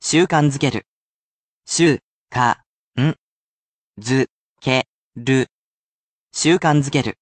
And, you are sure to enjoy our resident computer robot, here to help you pronounce words along the way.